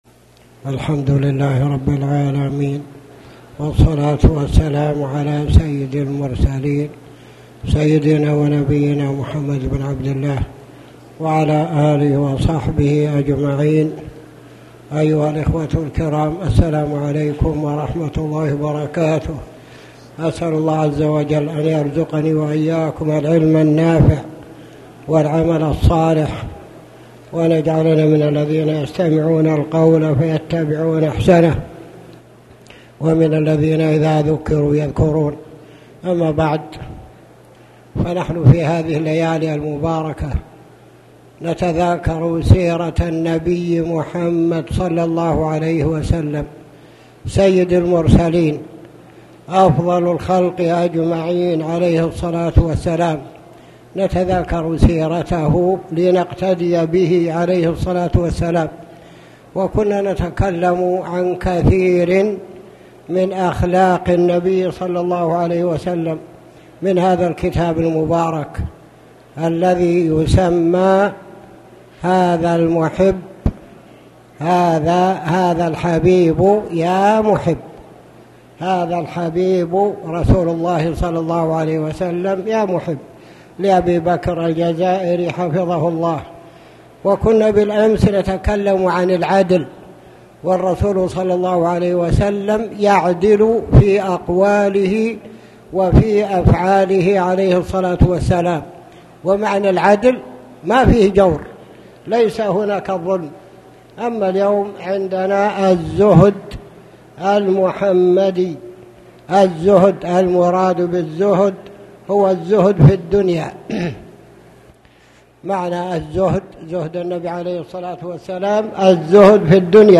تاريخ النشر ١١ محرم ١٤٣٩ هـ المكان: المسجد الحرام الشيخ